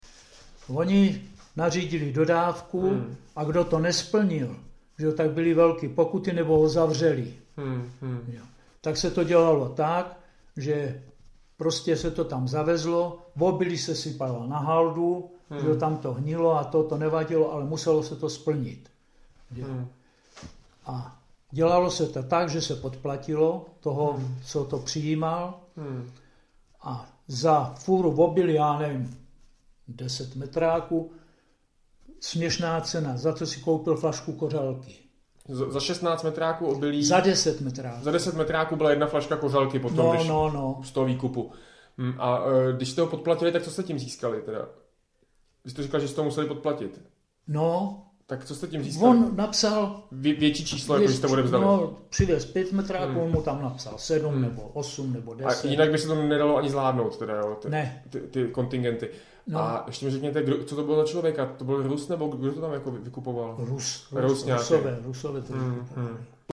Klip "Dodávky Sovětům" z vyprávění pamětníka